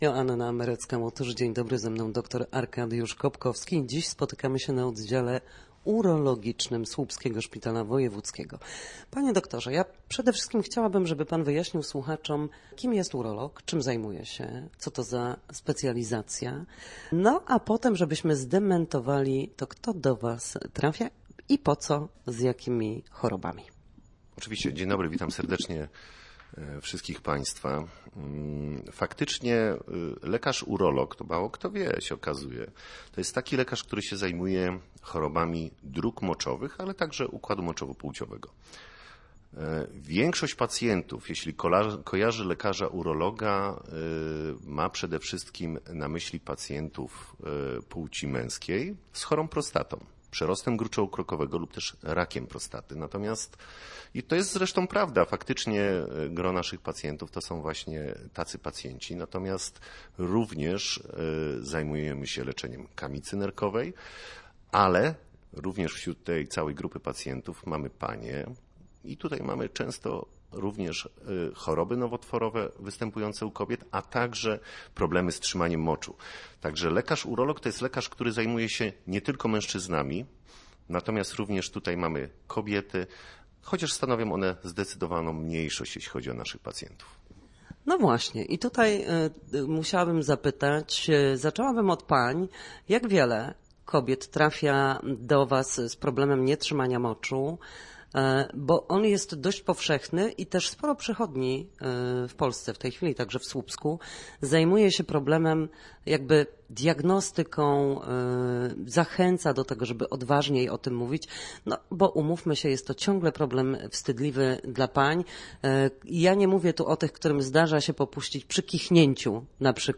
W każdą środę, w popołudniowym paśmie Studia Słupsk Radia Gdańsk, dyskutujemy o tym, jak wrócić do formy po chorobach i
W audycji „Na zdrowie” nasi goście – lekarze i fizjoterapeuci – odpowiadają na pytania dotyczące najczęstszych dolegliwości, podpowiadają, jak leczyć się w warunkach domow